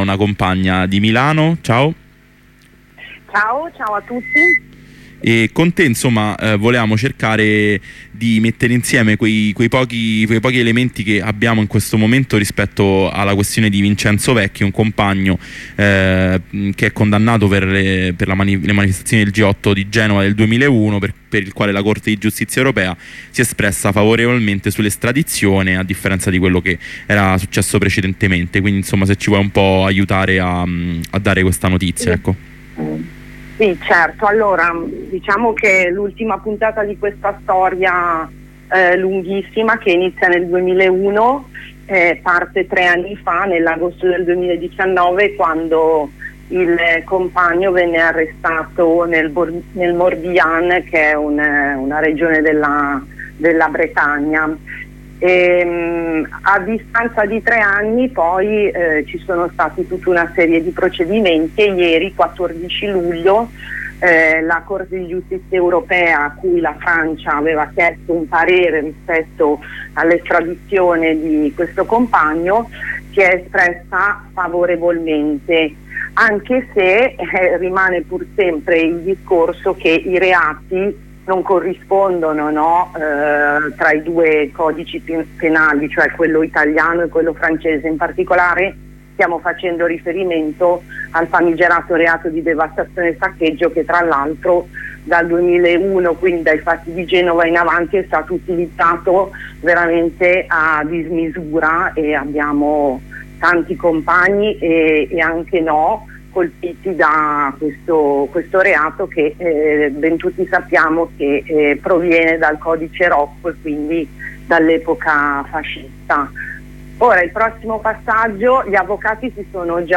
Compagna di Milano